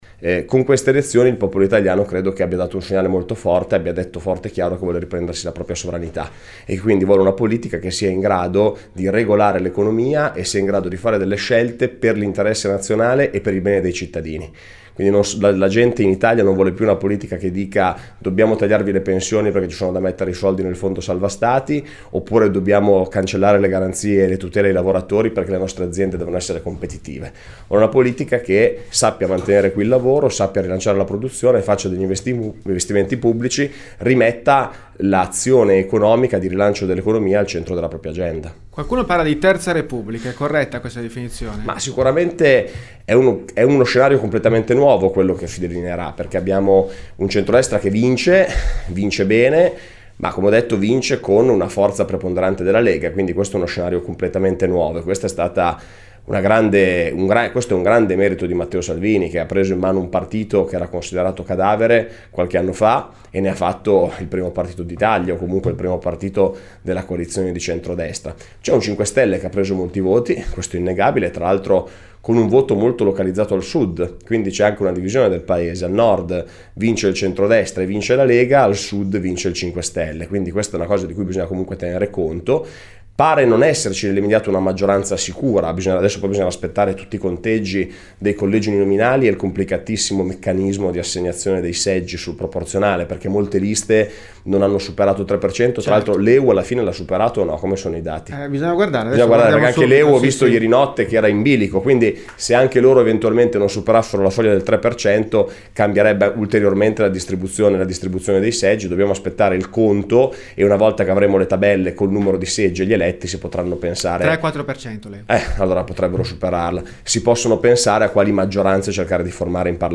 Di seguito l’audio integrale dell’intervista a Riccardo Molinari durante la diretta elettorale di Radio Gold che potete continuare a seguire live anche sulla nostra pagina Facebook.